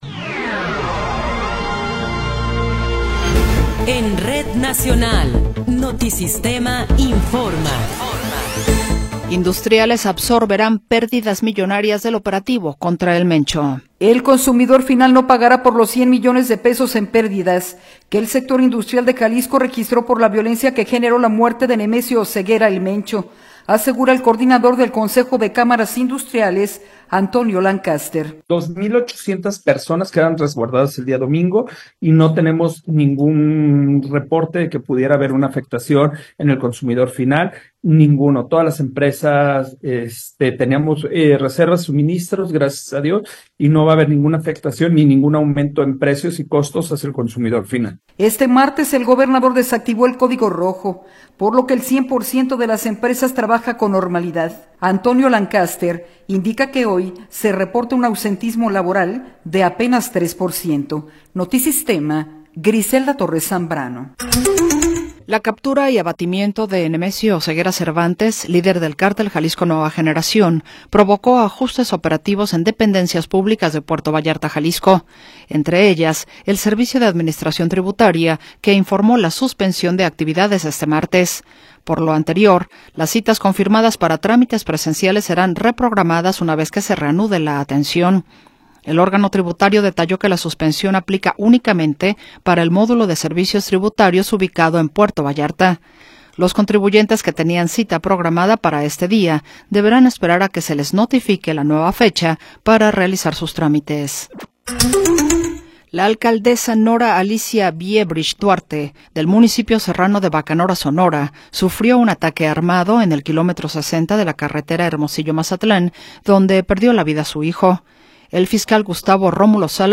Noticiero 16 hrs. – 24 de Febrero de 2026